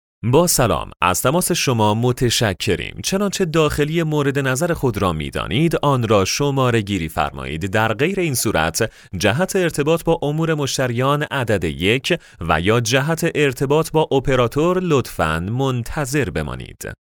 Male
Young
Adult
Now, he has his own home studio and can energetically read and record any type of script you can imagine.
IVR